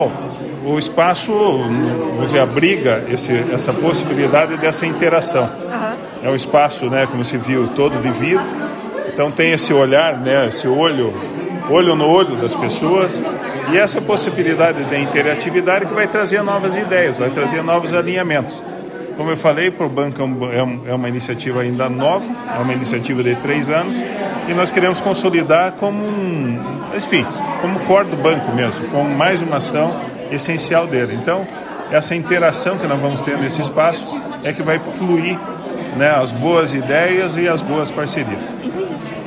Diretor-Presidente do BRDE, Wilson Bley Lipski: